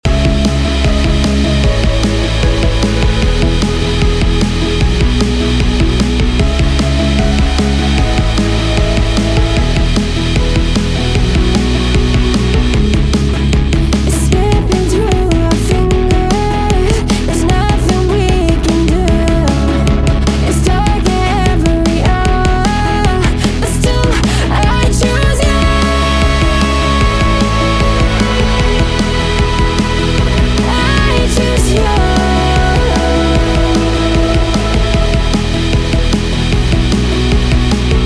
Lots of effect automation and probably way too much tuning on the vocals but whatever I like it this way sue me. Most of this is just Stock Ableton effects. It's also quite loud.